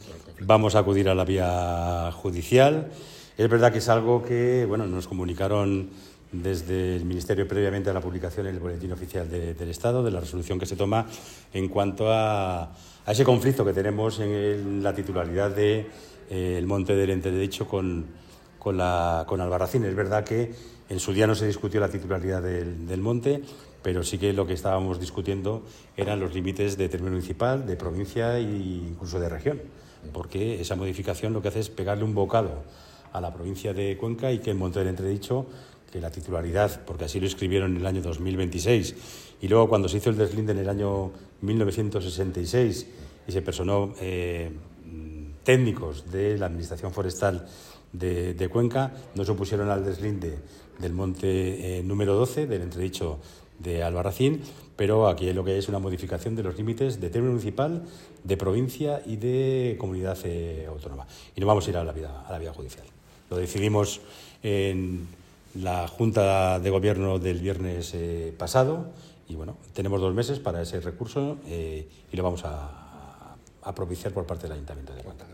Corte-Dario-Dolz-sobre-deslinde-Albarracin.mp3